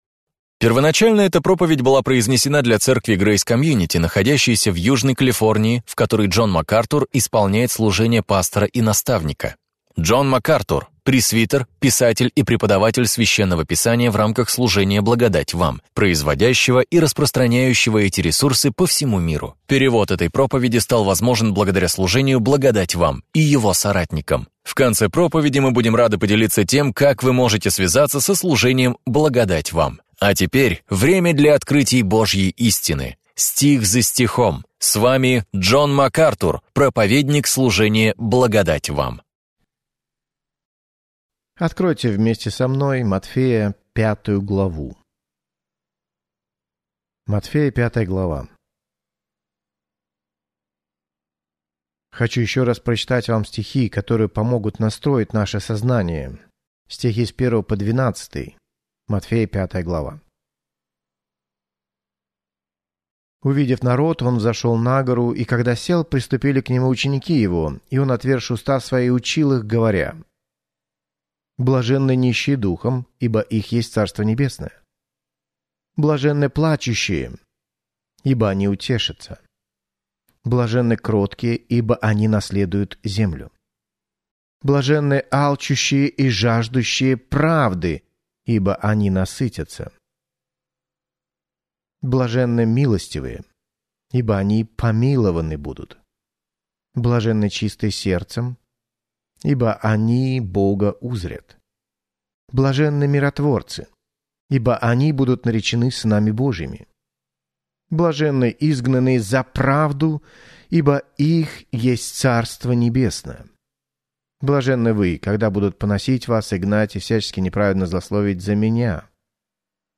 Проповеди МакАртура